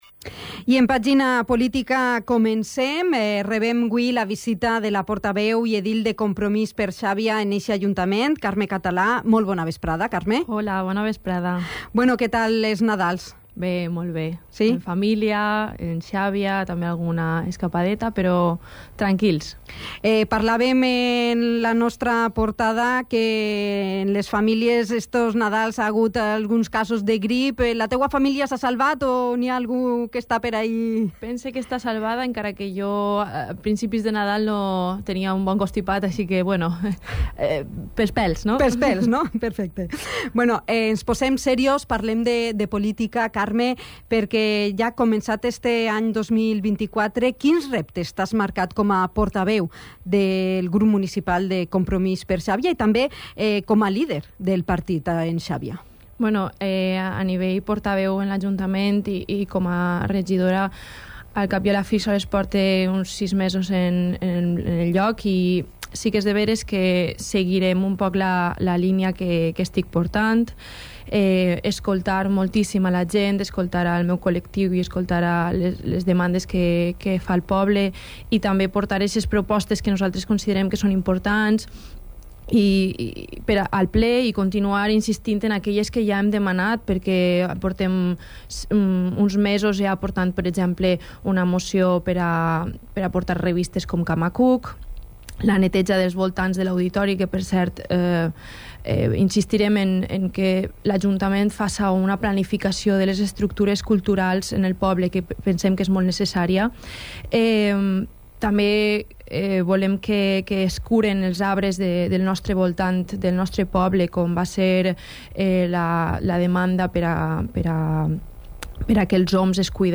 La portavoz y edil de Compromís per Xàbia, Carme Català ha visitado la redacción informativa de Dénia FM, para realizar balance de la gestión política municipal
Entrevista-Carme-Catala.mp3